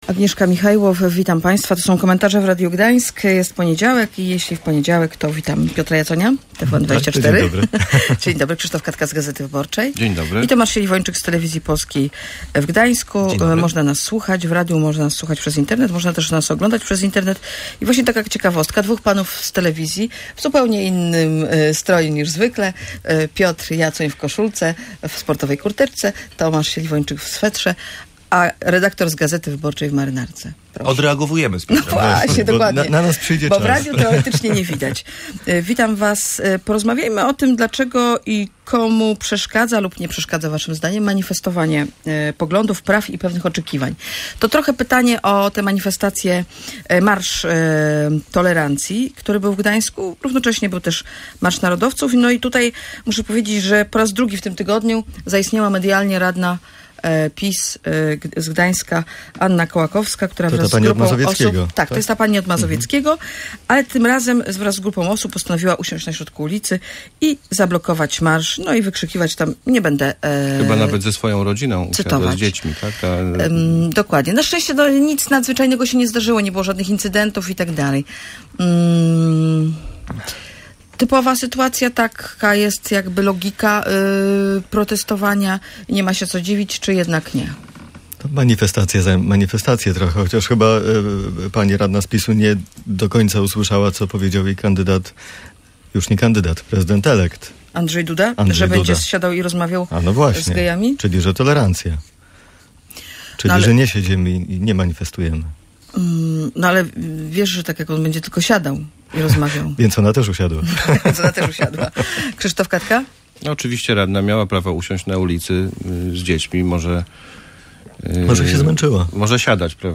On wzywał do tolerancji, dziwili się komentatorzy Radia Gdańsk. W ten sposób podsumowali zachowanie radnej PiS z Gdańska, która ze swoją rodziną zablokowała na kilkanaście minut sobotni marsz środowiska LGBT.